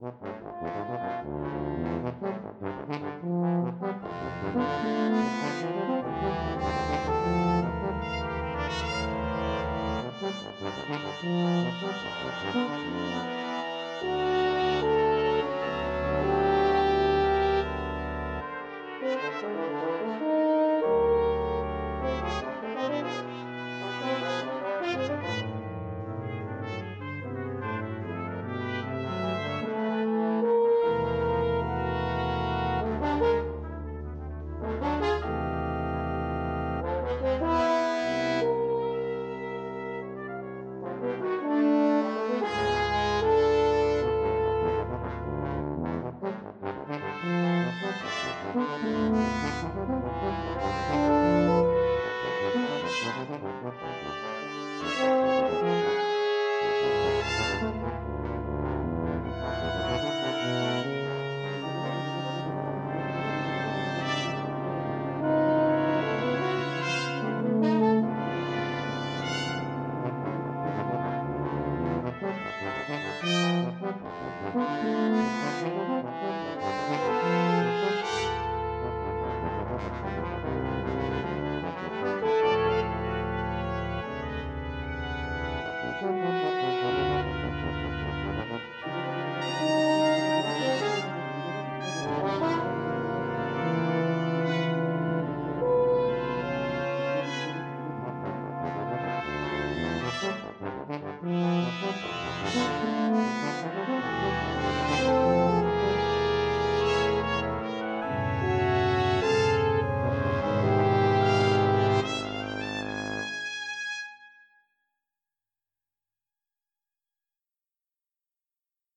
mp3 (computer generated)